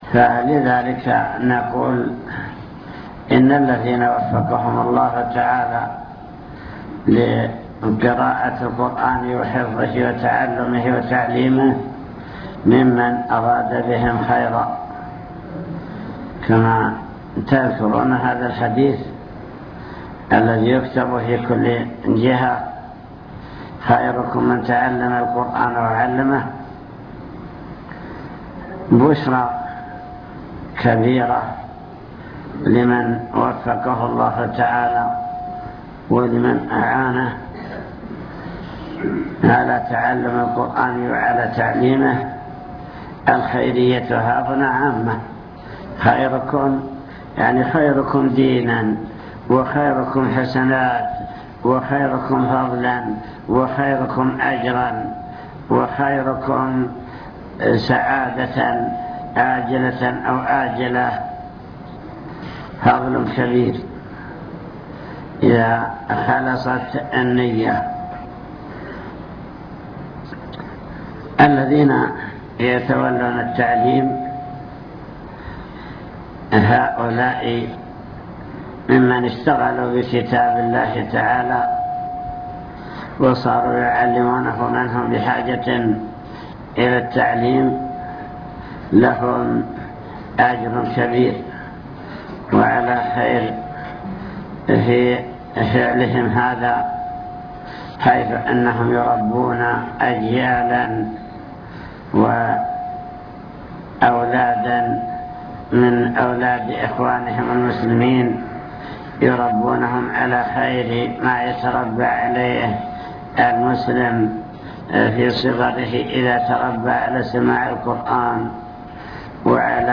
المكتبة الصوتية  تسجيلات - لقاءات  لقاء جماعة تحفيظ القرآن بالحريق